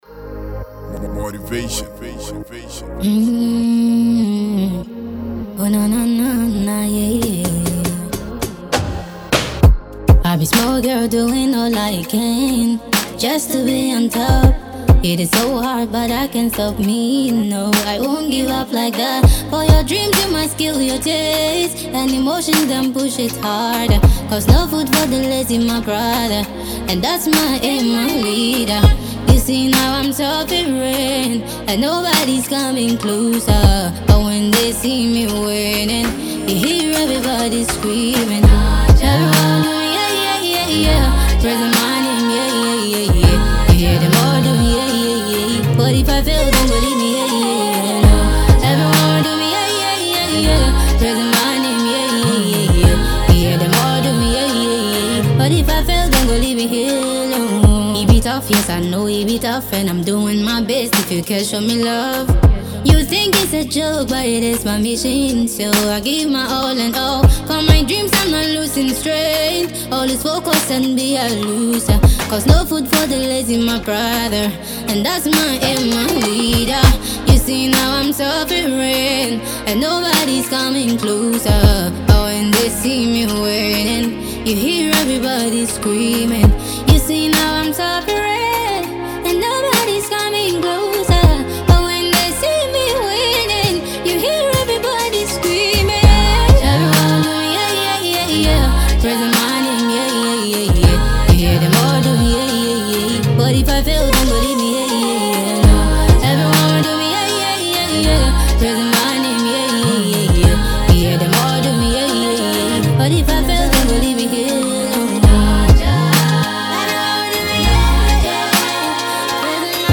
an eclectic blend of genres
soulful voice